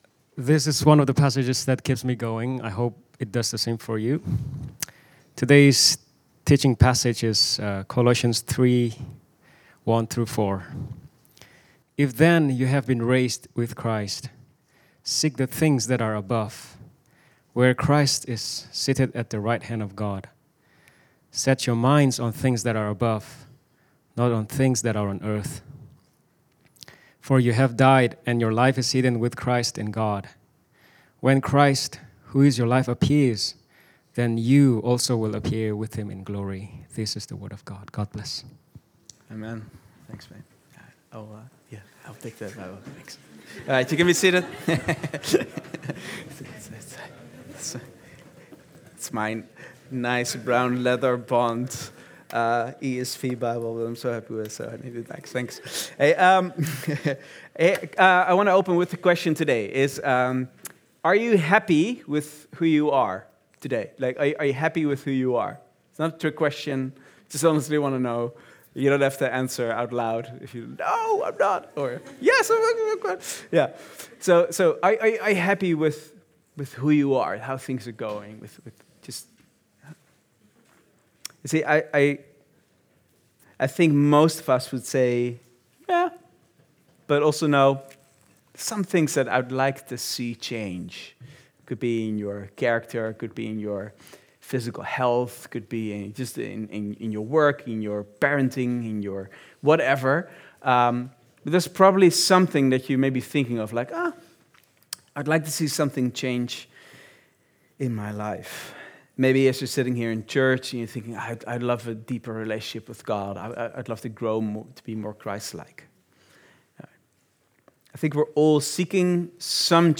Vineyard Groningen Sermons Gospel Community | Part 5 | Say Yes to the Dress!